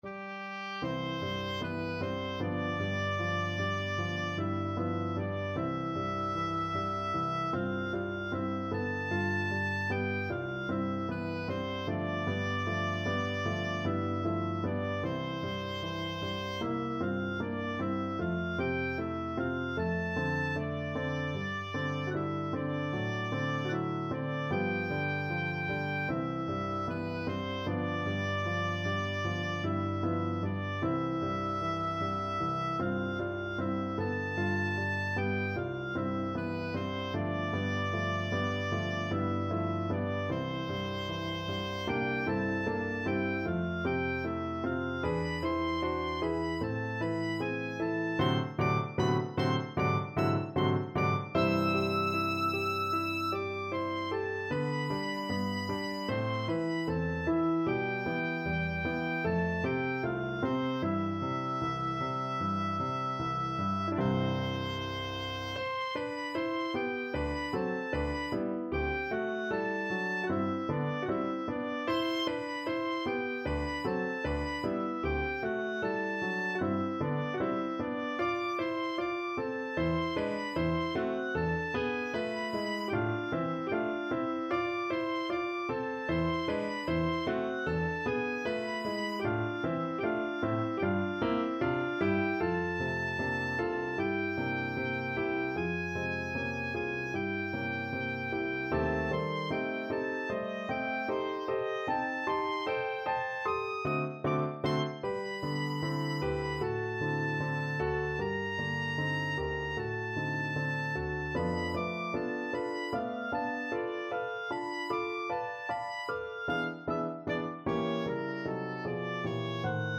C major (Sounding Pitch) (View more C major Music for Oboe )
Lento ma non troppo = c.76
2/4 (View more 2/4 Music)
F5-E7
Classical (View more Classical Oboe Music)